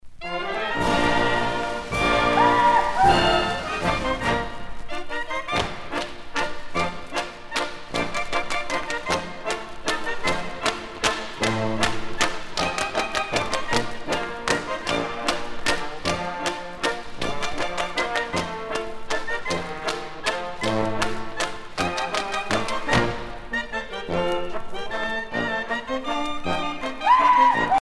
danse : ländler
Pièce musicale éditée